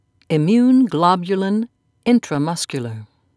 (im'mune glob'u-lin)